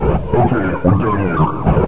player_dead2.ogg